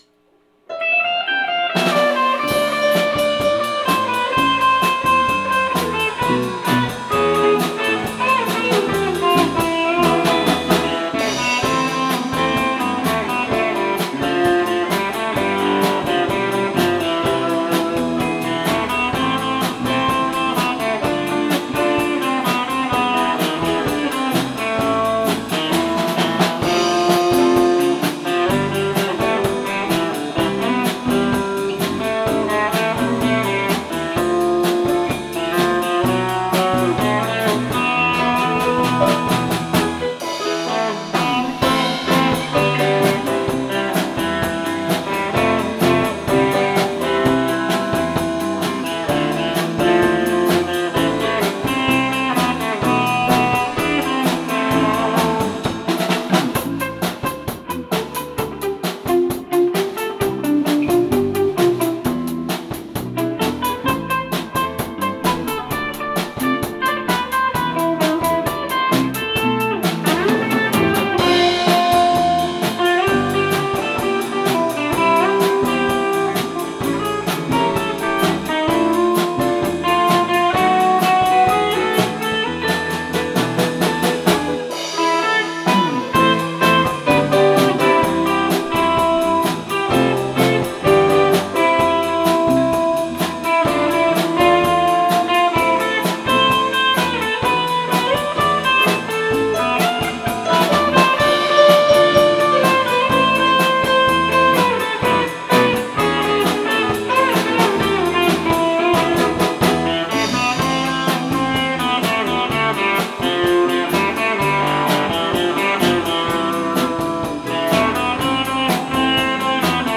場所：ベンチャーズハウス「六絃」
８月３日(土)「ベンチャーズハウス六絃」にて「30th Live 2019 Summer Live」を開催しました、